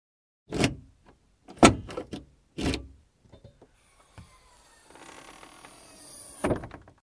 Звуки багажника
Звук открытия багажника ключом у Лады семерки